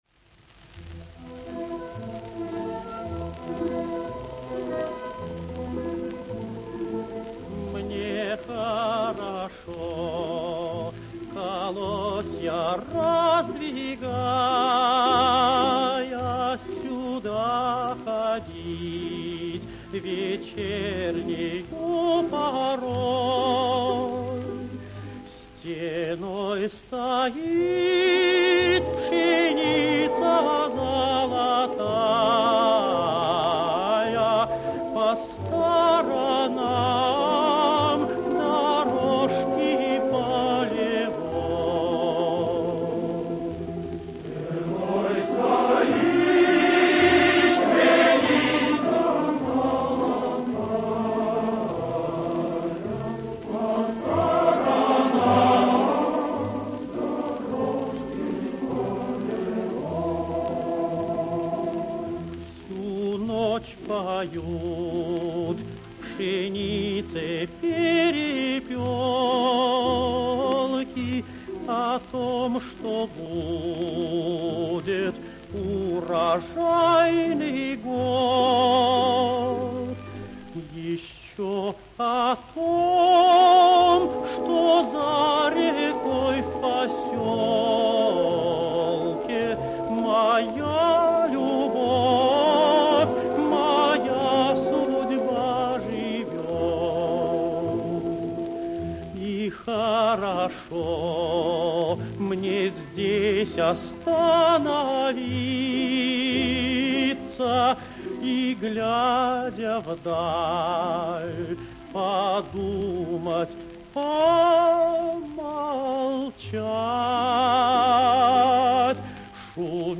Красивая лирическая песня